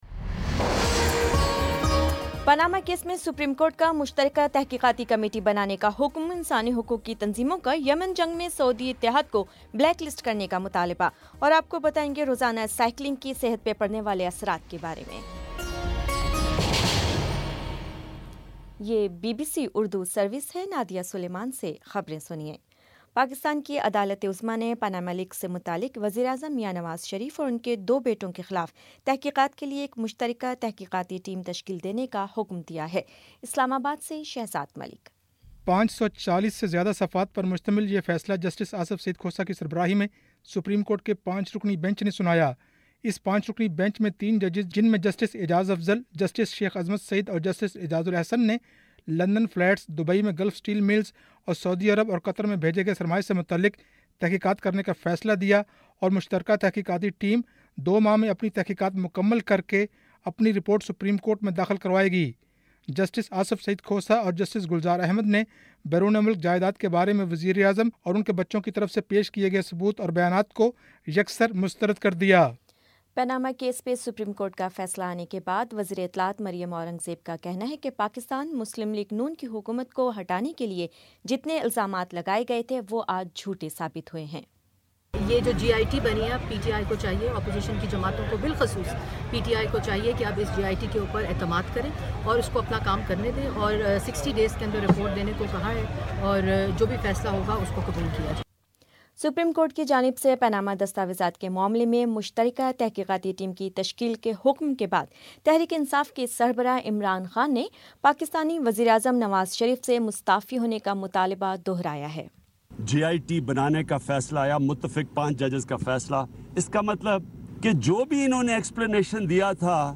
اپریل 20 : شام چھ بجے کا نیوز بُلیٹن